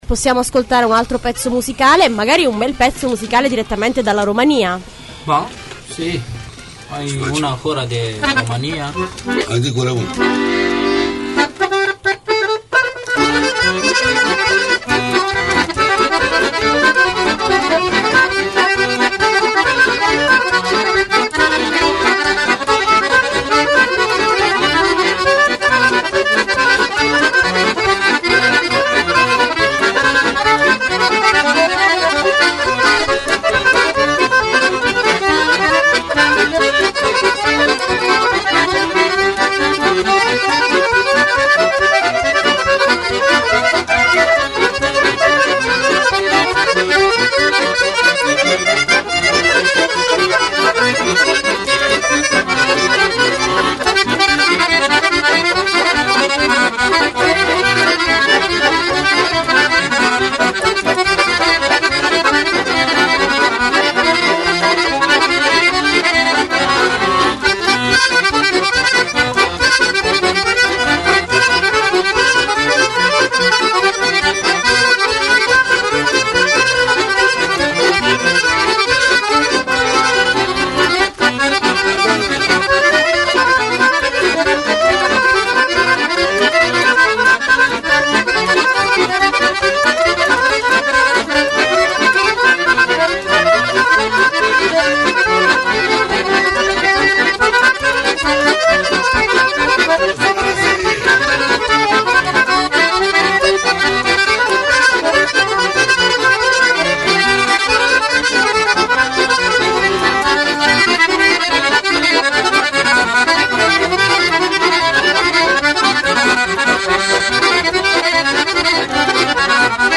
sono musicisti di strada provenienti da Buzeu, in Romania, vivono in Italia da nove anni.
Una brutta disavventura, quella dei tre musicisti di strada, che sono stati ospiti nei nostri studi di via Berretta Rossa nella trasmissione “Benvenuti in Italia” dove hanno presentato un repertorio di musiche popolari italiane e romene.